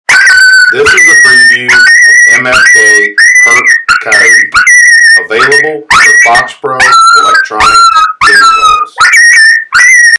MFK Hurt Coyote – 16 bit
Recorded with the best professional grade audio equipment MFK strives to produce the highest
The Big Difference- Our one-of-a-kind live coyote library naturally recorded at extremely close